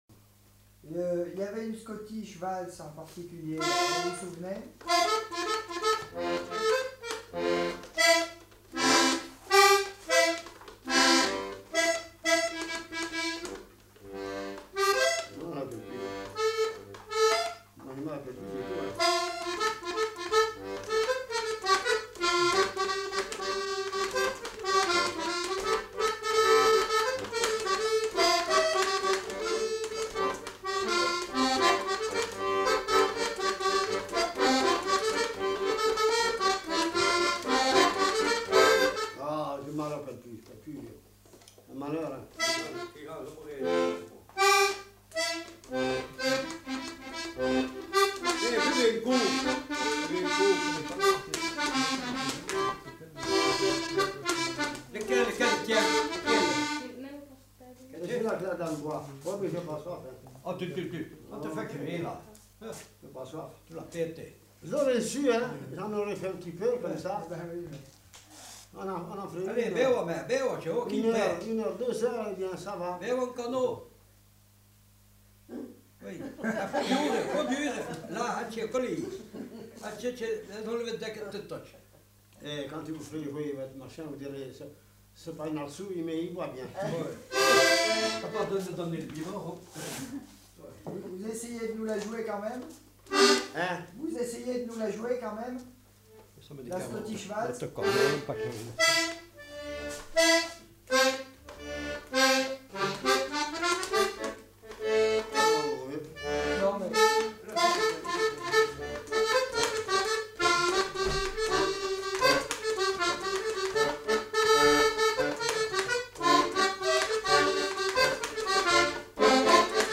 Scottish-valse
Aire culturelle : Auvergne
Lieu : Peschadoires
Genre : morceau instrumental
Instrument de musique : accordéon
Danse : scottish-valse